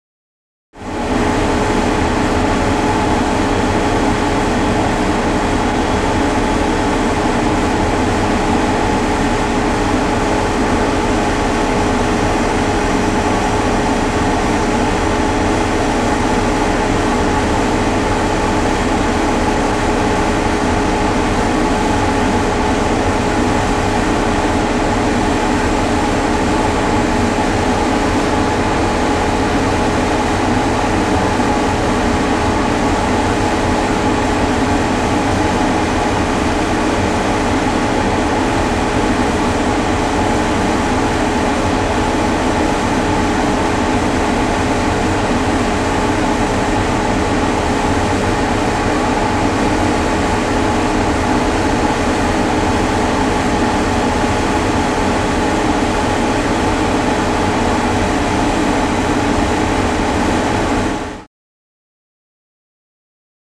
Ship; Ventilator Fan; Ships Ventilator Fan.